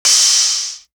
EDM Rinse Low.wav